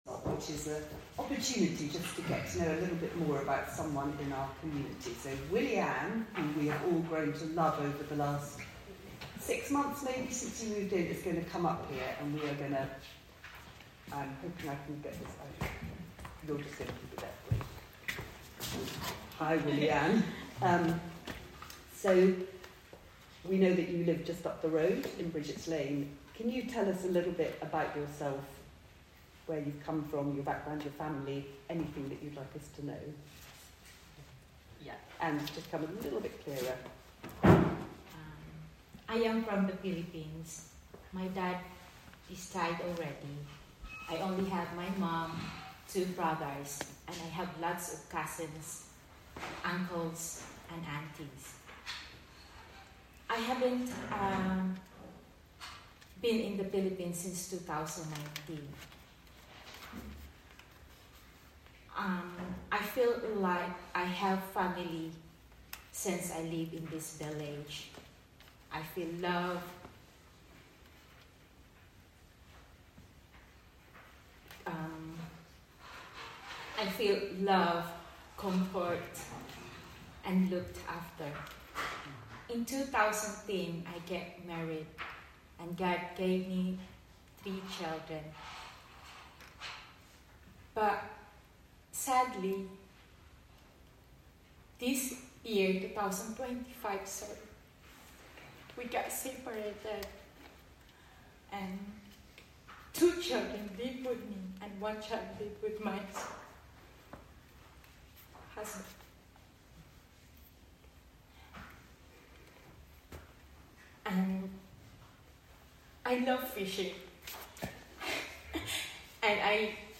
Apologies as the quality is not as clear as we would hope but it wasn’t originally recorded to share.